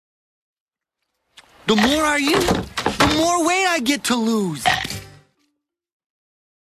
Carlos Alazraqui ( Rocko ) | Tom Kenny ( Heffer Wolfe )